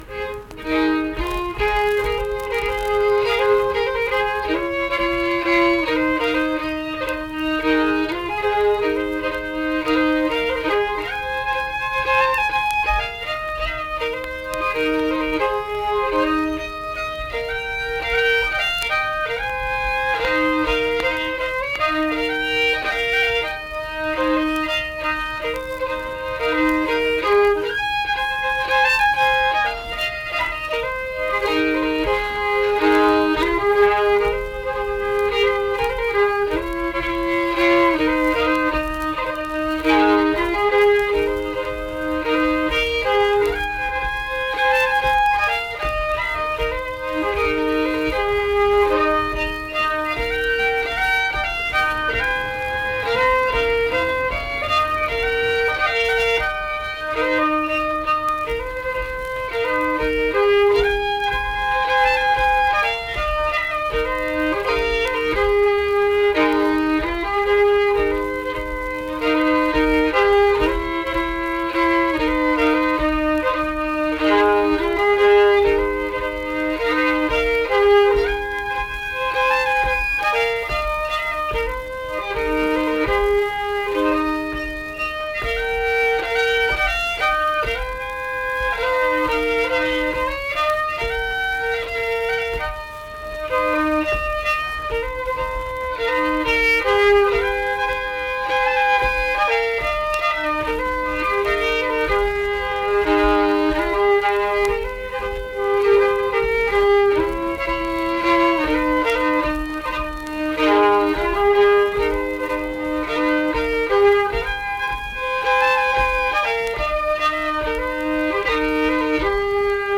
Unaccompanied fiddle music
Instrumental Music
Fiddle
Marlinton (W. Va.), Pocahontas County (W. Va.)